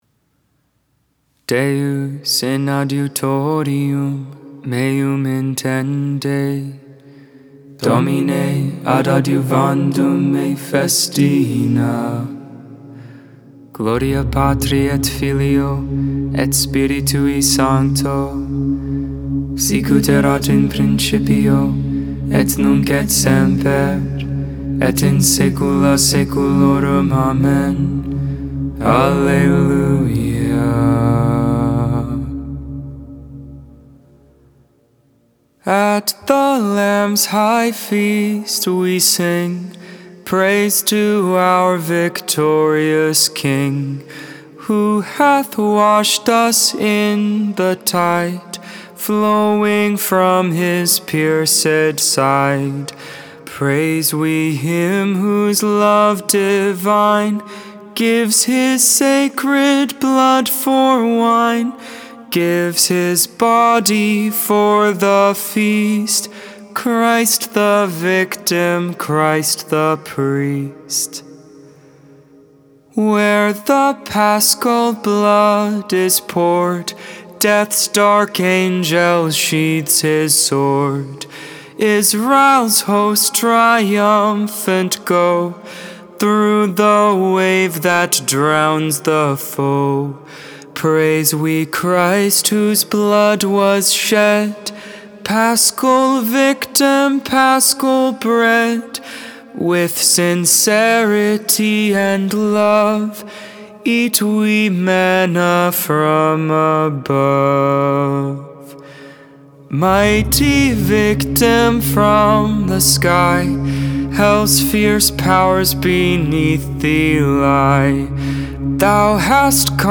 11.5.22 Vespers, Saturday Evening Prayer